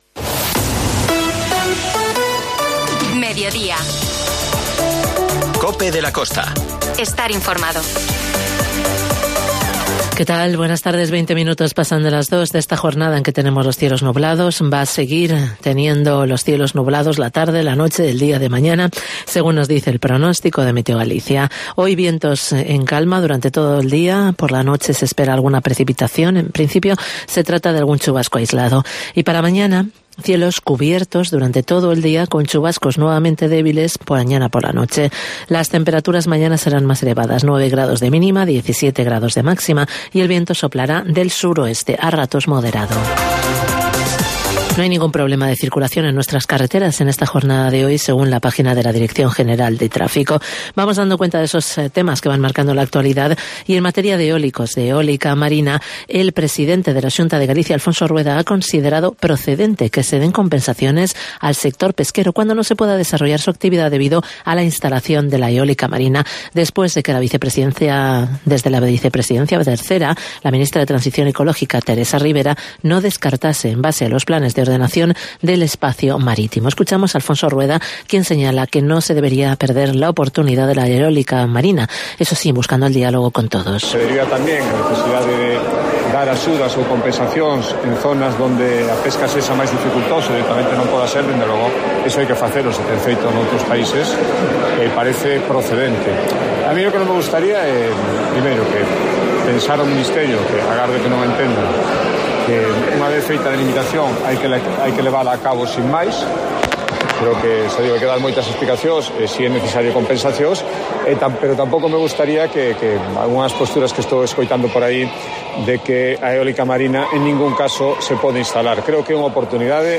COPE de la Costa - Ribadeo - Foz INFORMATIVO